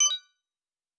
Coins (27).wav